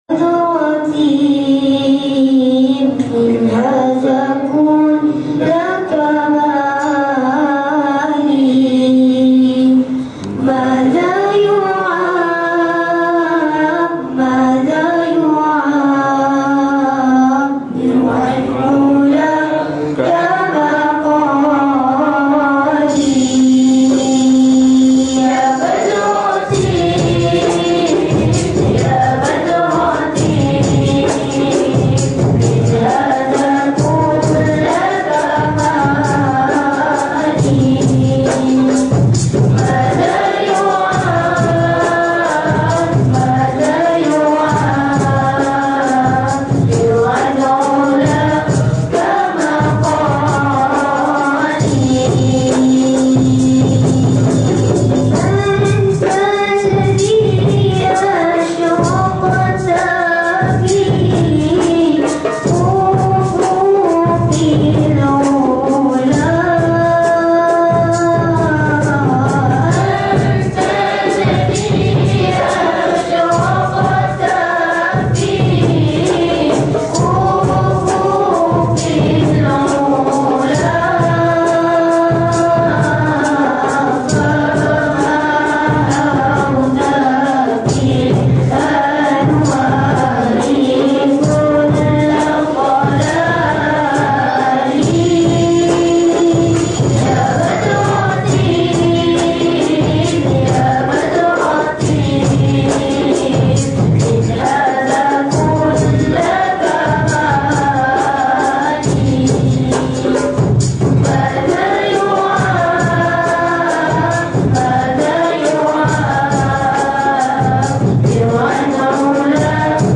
Malay Sufi Music